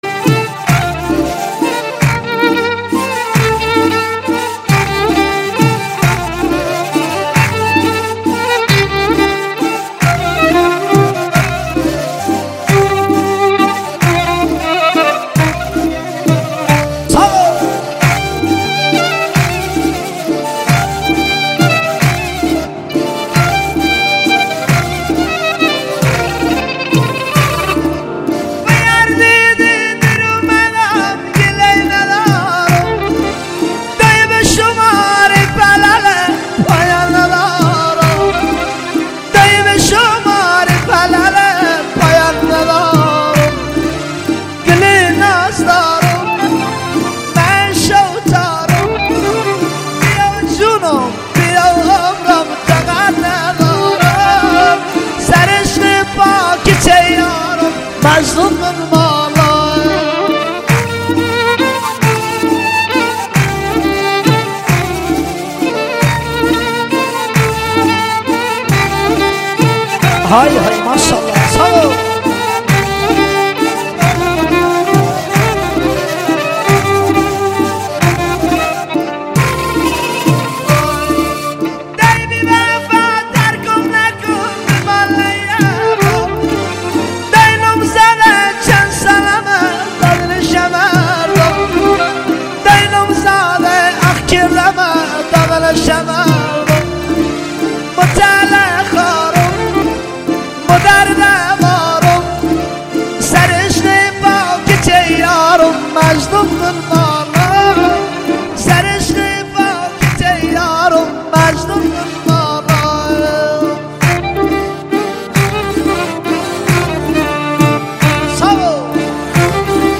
ترانه لری بختیاری